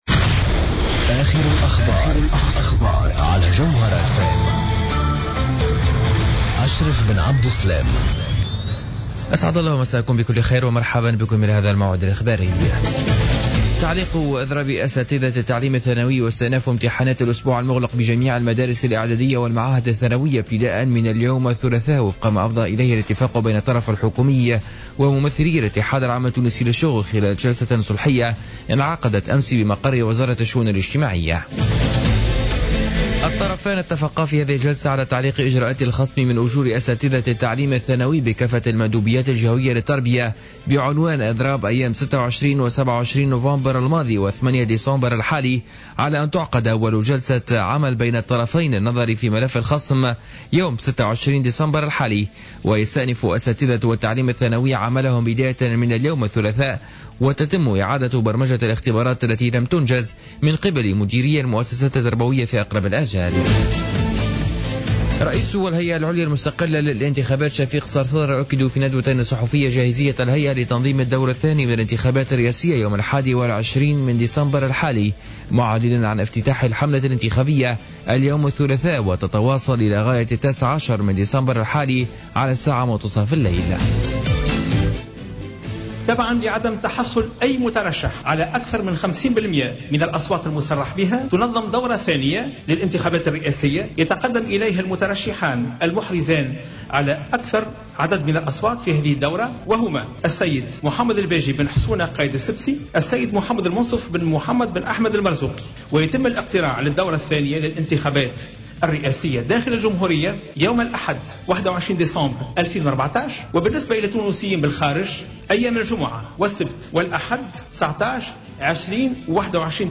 نشرة أخبار منتصف الليل ليوم الثلاثاء 09-12-14